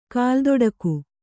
♪ kāldoḍaku